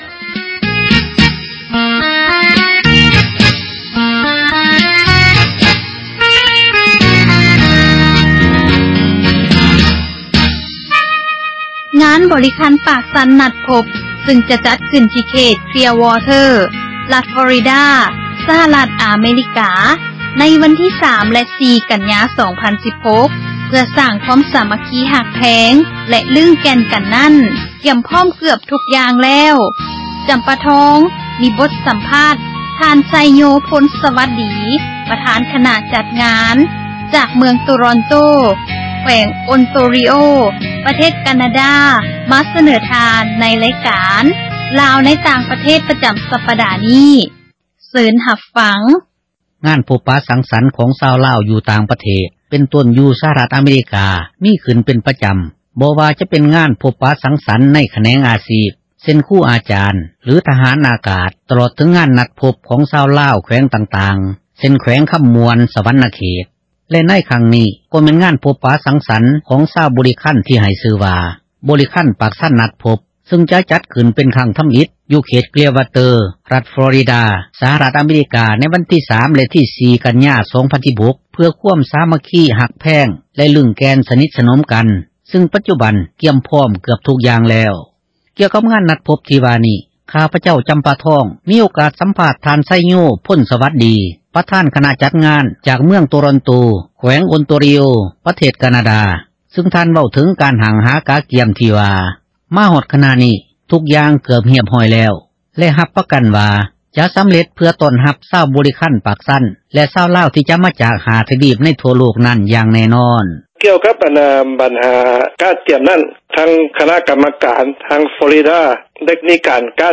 ສຳພາດ